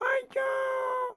Assets / Audio / SFX / Characters / Voices / PigChef / PigChef_13.wav